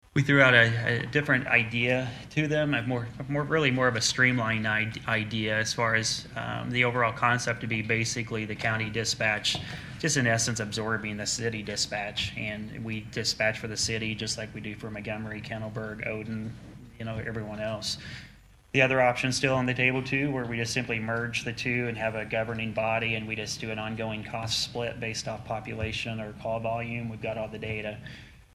Commissioner Nathan Gabhart provided an update on the Central Dispatch at yesterday’s Daviess County Board of Commissioners Meeting.